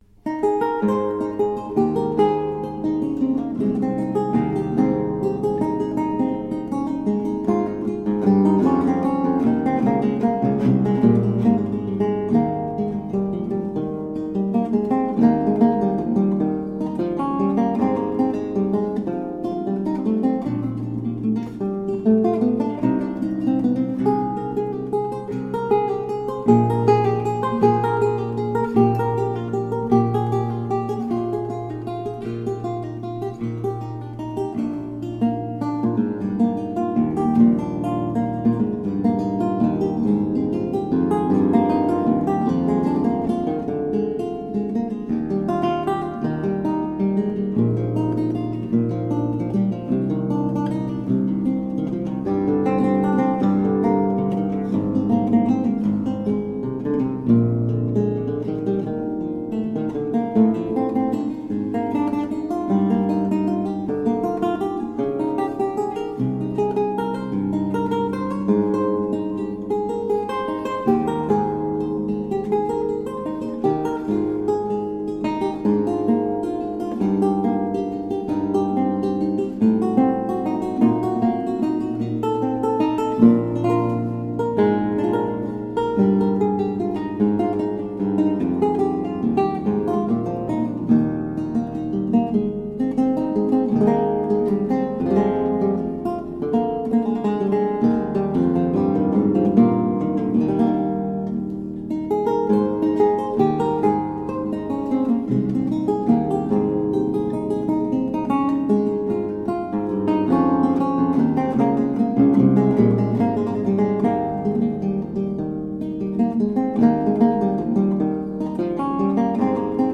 A marvelous classical spiral of lute sounds.
Classical, Baroque, Instrumental, Lute
Theorbo